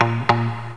RIFFGTR 08-L.wav